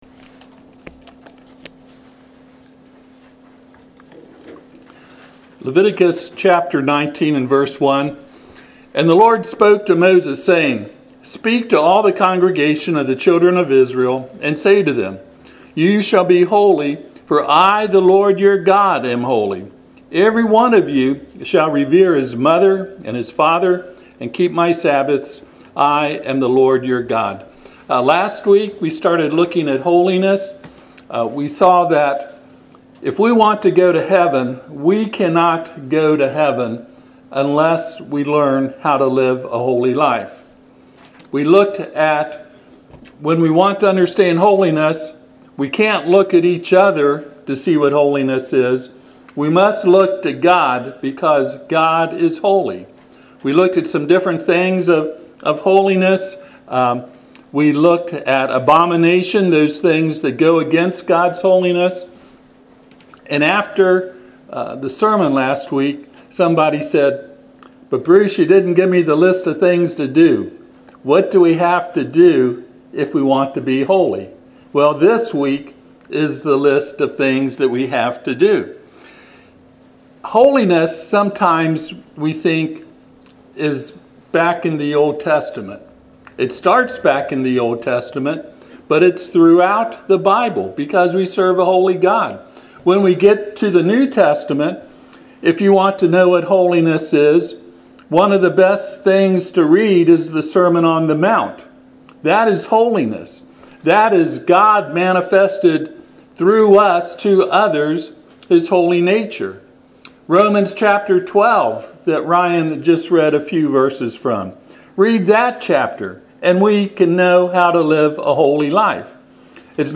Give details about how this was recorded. Passage: Leviticus 19:1-2 Service Type: Sunday AM